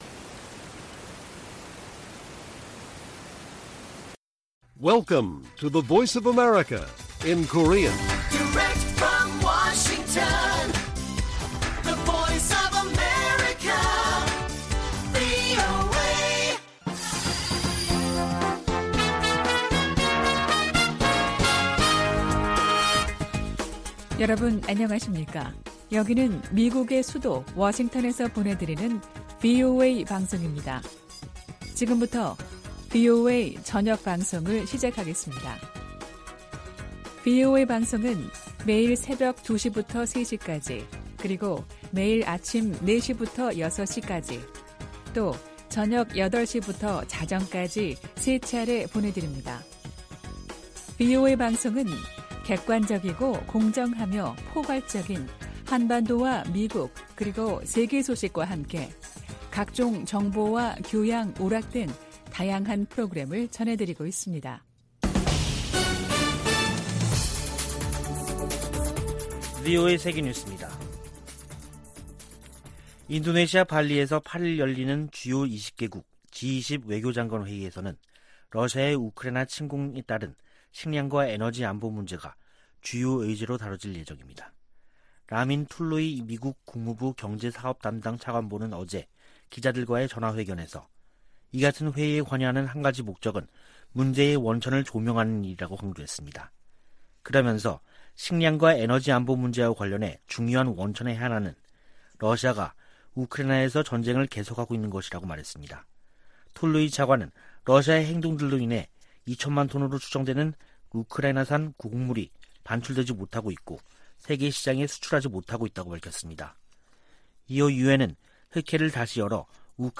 VOA 한국어 간판 뉴스 프로그램 '뉴스 투데이', 2022년 7월 6일 1부 방송입니다. 바이든 정부의 동맹 강화 의지는 인도태평양 지역에서 가장 뚜렷하다고 미 국무부 동아시아태평양 담당 차관보가 말했습니다. 국제원자력기구(IAEA) 사무총장이 북한 핵 문제를 해결되지 않은 집단적 실패 사례로 규정했습니다. 미 하원에서 북한 등 적국들의 ‘회색지대 전술’에 대응을 개선토록 하는 법안이 추진되고 있습니다.